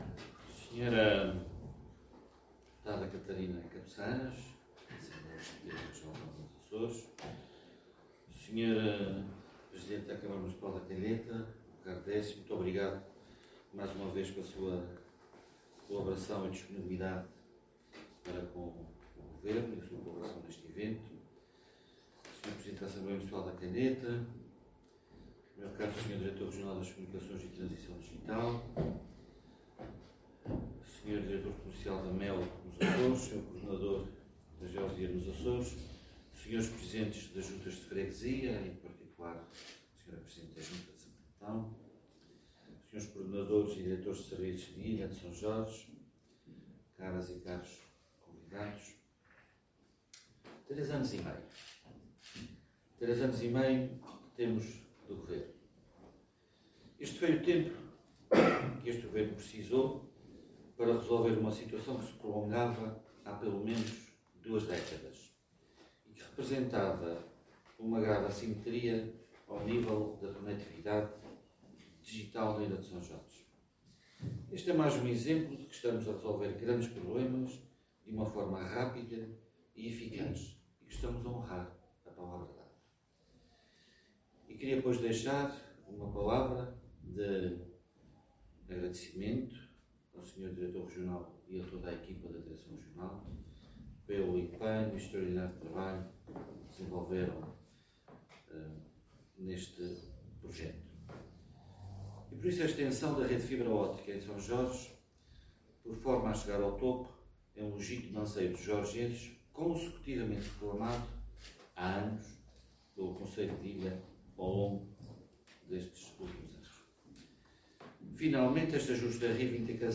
O Vice-Presidente do Governo falava na Câmara Municipal da Calheta